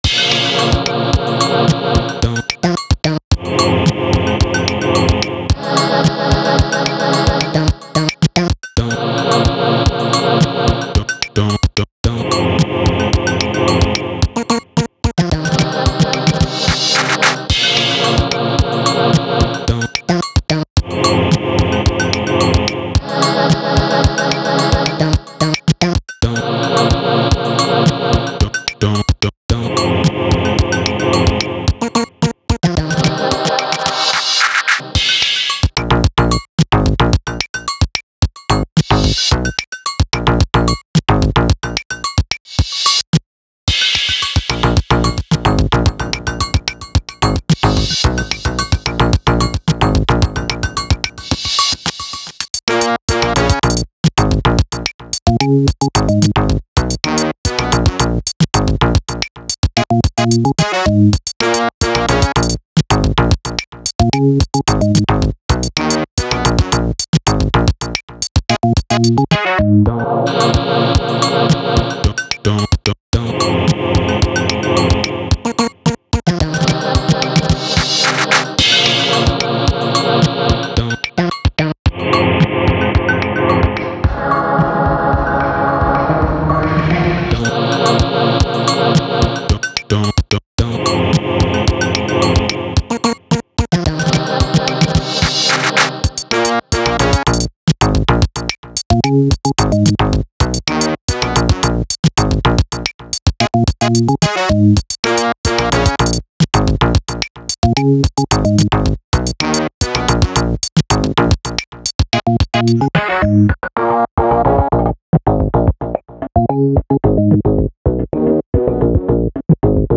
OK , loaded them into Koala on iPad and made these few loops whilst I should have been working, ooops! :smiley: